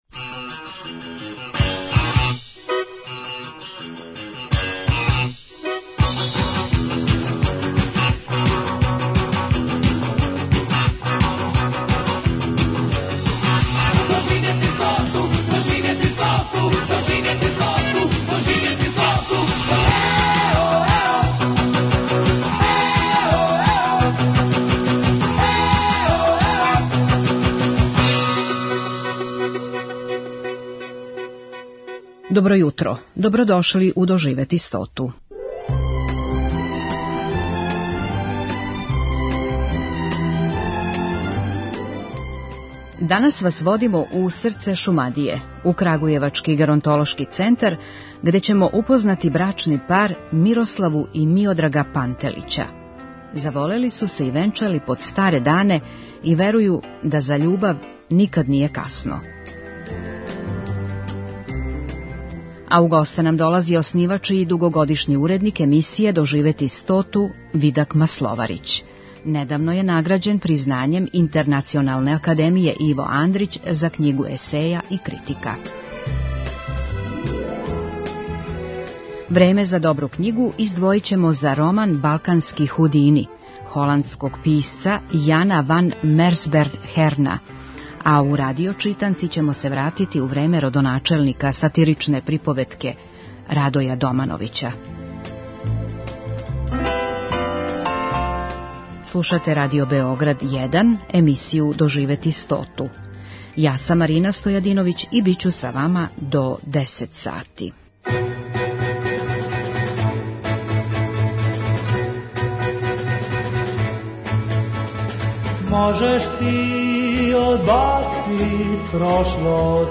Емисија "Доживети стоту" Првог програма Радио Београда већ двадесет четири године доноси интервјуе и репортаже посвећене старијој популацији. У разговорима с истакнутим стручњацима из области социјалне политике, економије, медицине, културног, јавног и спортског живота, емисија се бави свим аспектима живота трећег доба.